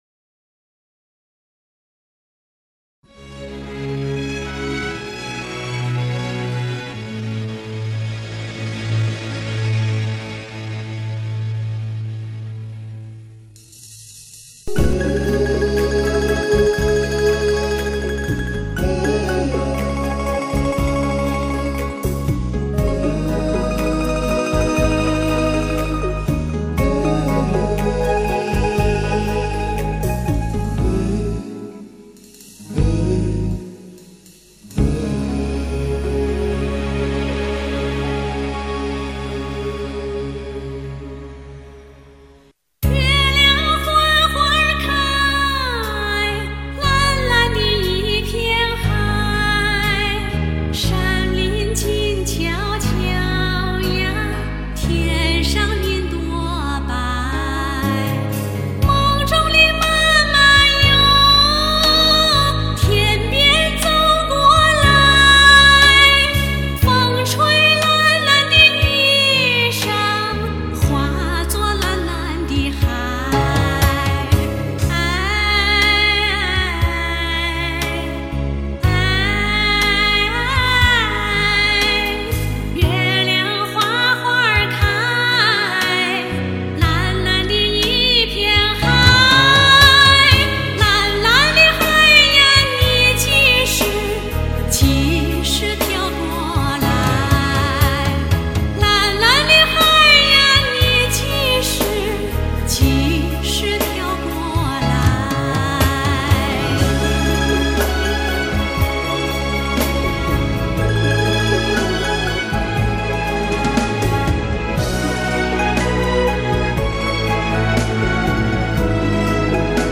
这是从血液、从骨子里流露出一种温柔，细腻和美到极致的忧郁，歌声在空中孤清地摇曳，让人心中微颤、魂不守舍。